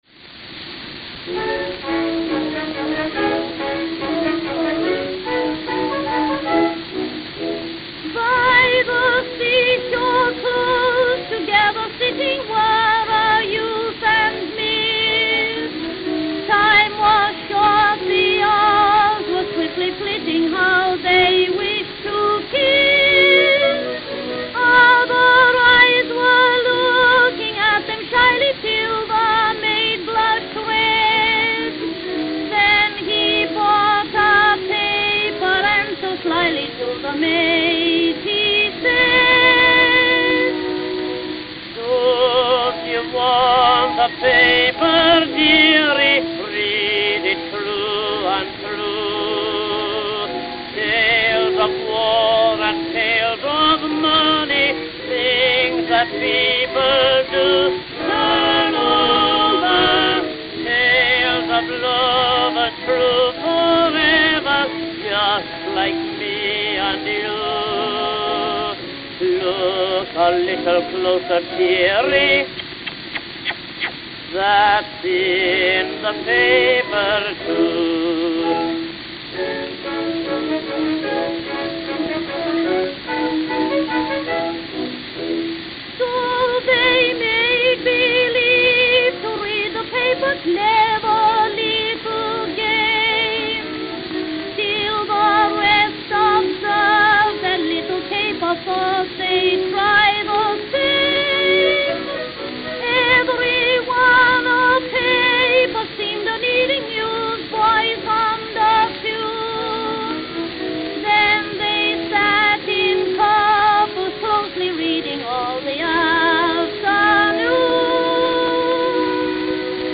Philadelphia, Pennsylvania (?)